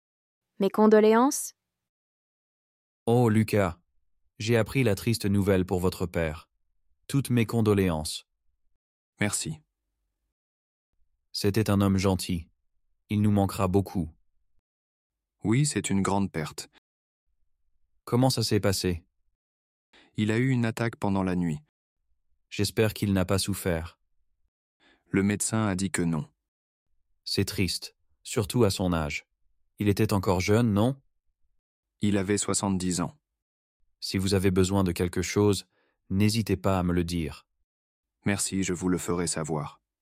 Dialogue en français – Mes condoléances (Niveau A2)